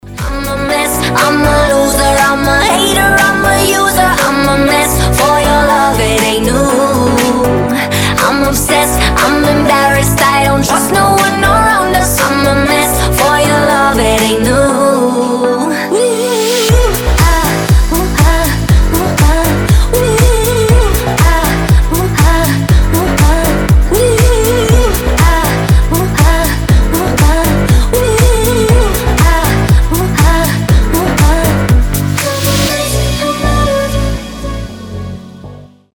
• Качество: 320, Stereo
громкие
красивый женский голос
house